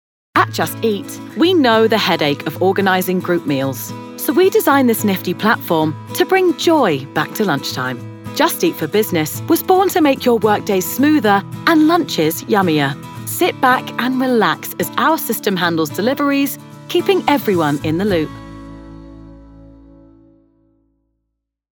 Inglés (Británico)
Versátil, Amable, Natural
Explicador
Her voice is youthful, husky, relatable, and authentic, and her natural accent is a London/Estuary accent.